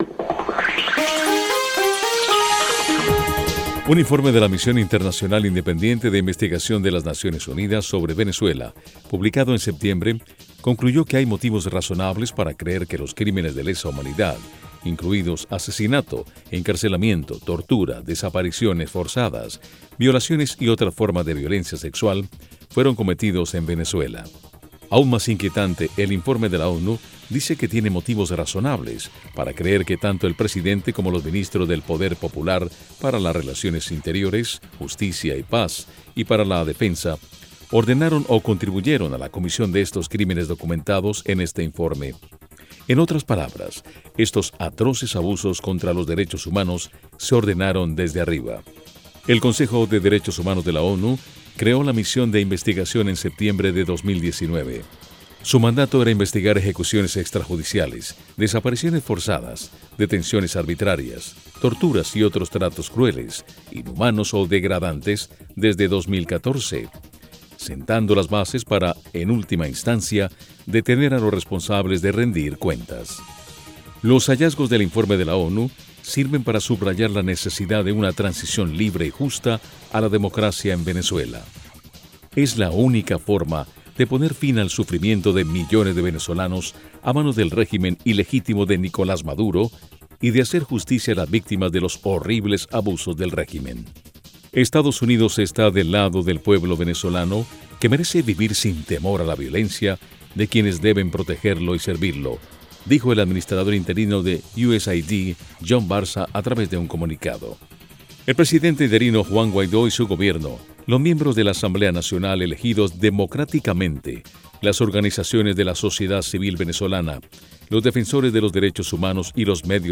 A continuación, un editorial que reflejaba las opiniones del gobierno de Estados Unidos: